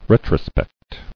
[ret·ro·spect]